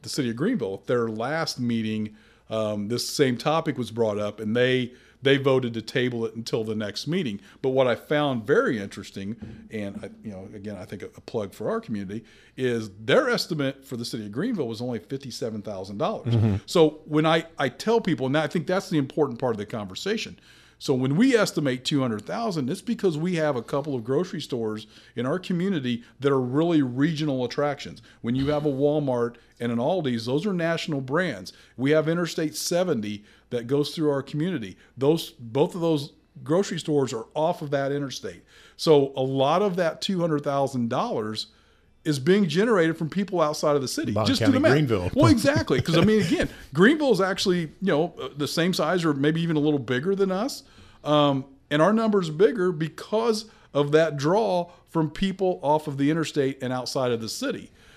Speaking on our podcast, “Talking about Vandalia,” Mayor Doug Knebel uses an example of how much revenue that costs Vandalia when compared to some other neighboring communities.